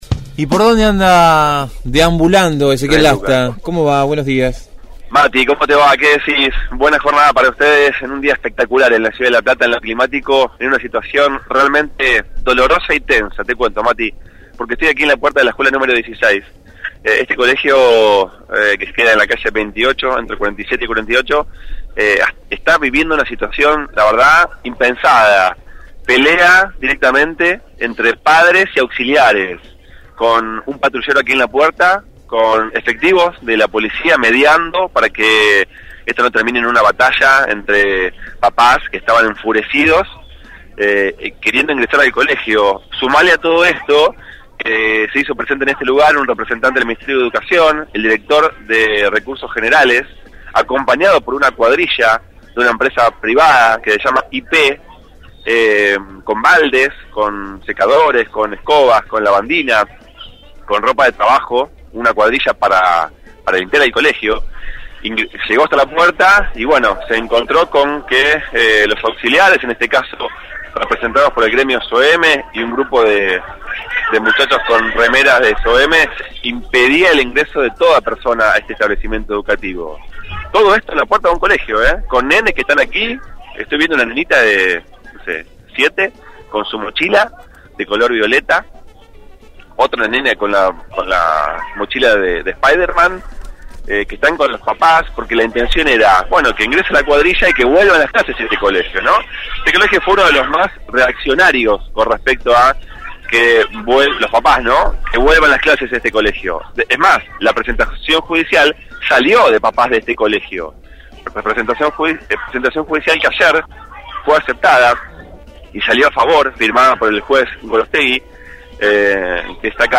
MÓVIL/ Auxiliares prohíben el ingreso de cuadrillas de limpieza a los colegios